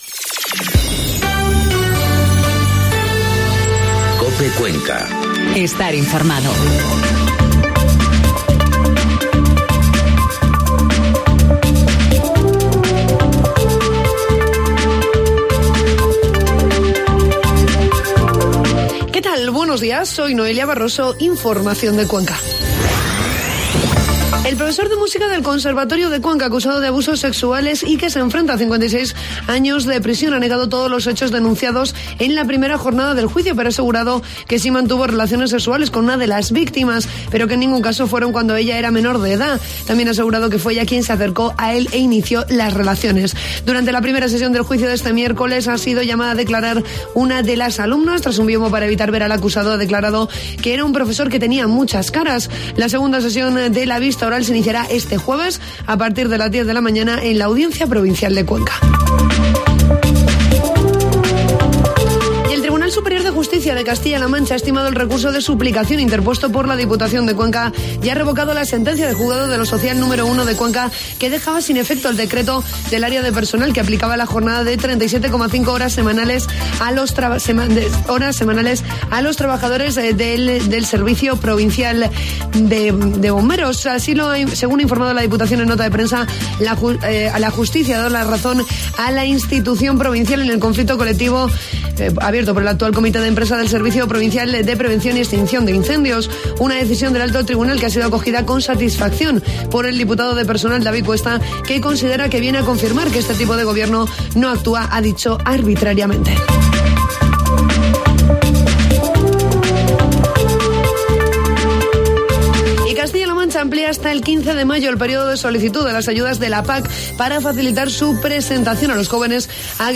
Informativo matinal COPE Cuenca 25 de abril